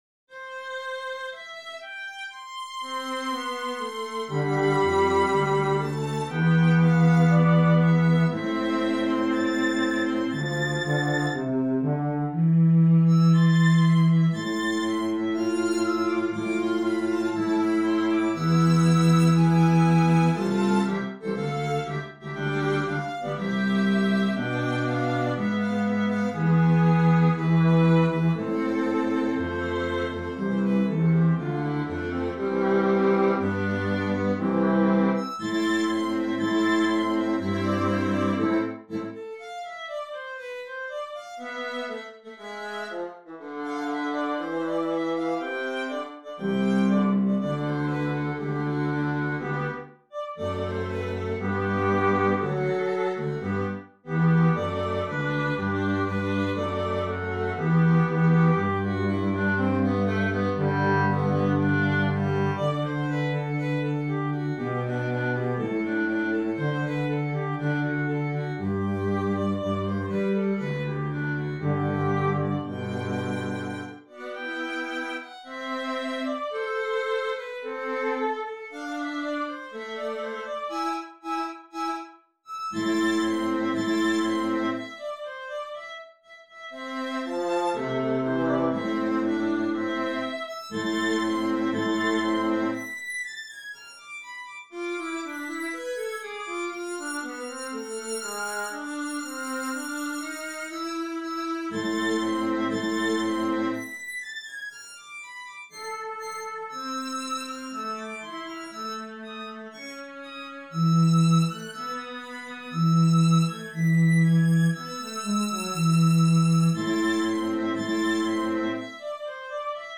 Trio for violin,viola and cello